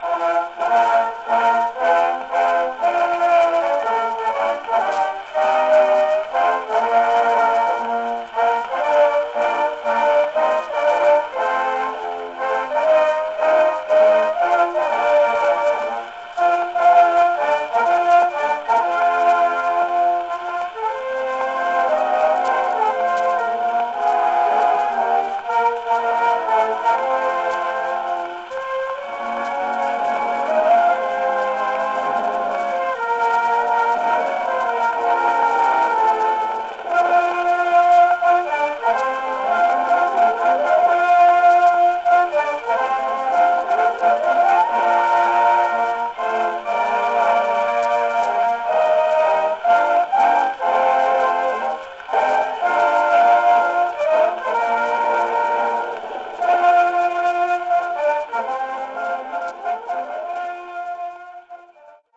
Давайте, кстати, послушаем, как звучала тогда сама «Марсельеза» — в исполнении оркестра французской Республиканской гвардии (записано в 1908 году в Париже, пластинка Zonophone X-2-20002):